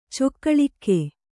♪ cokkaḷikke